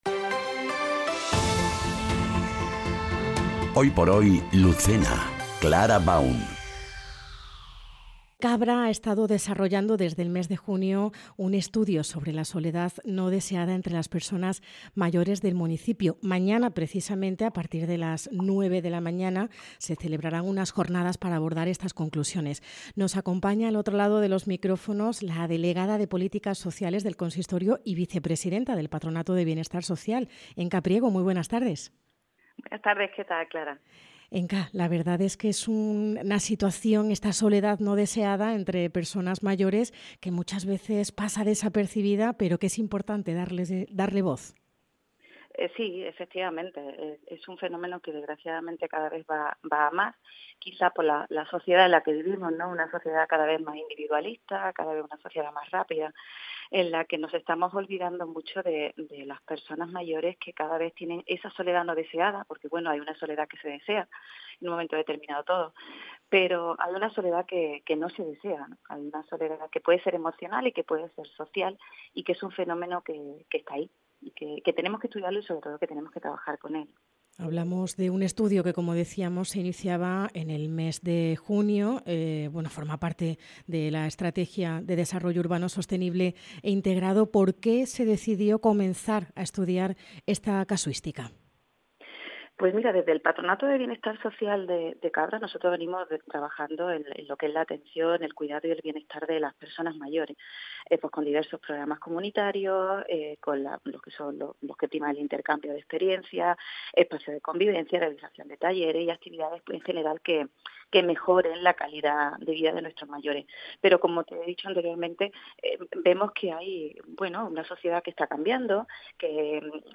Entrevista | Enca Priego - Estudio Soledad Mayores Cabra